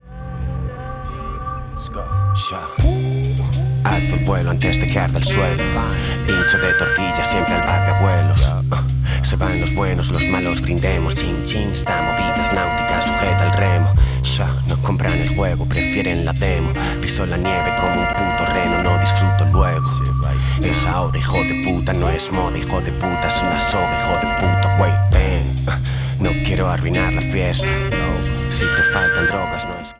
Hip Hop/Dj Tools